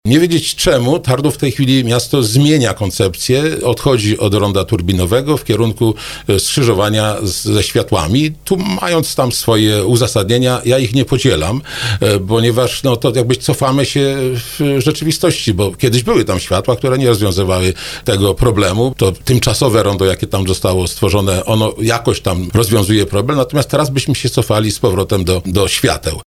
Ryszard Pagacz, który był gościem Słowo za Słowo dziwi się, że władze miasta decydują się na taki krok, tym bardziej, że może to oznaczać utratę dofinansowania w wysokości 25 mln zł, które przekazało właśnie Województwo Małopolskie.